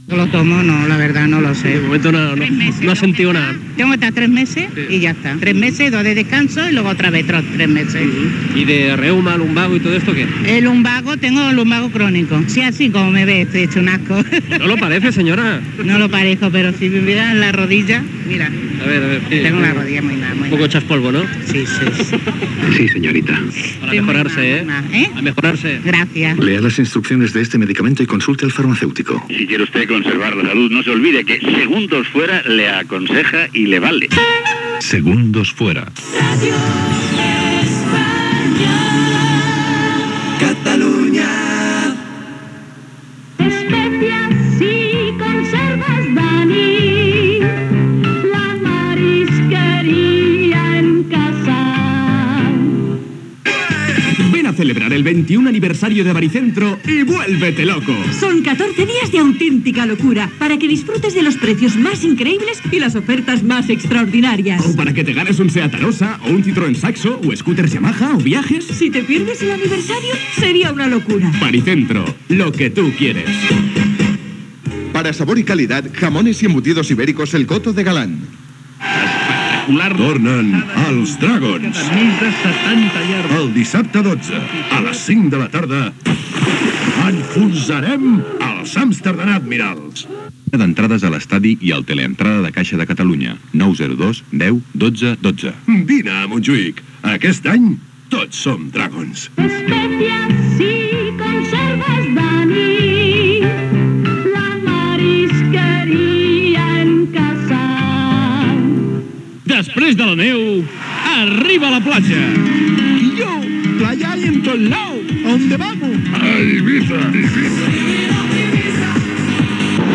Publicitat i promoció d'un viatge a Eivissa.
FM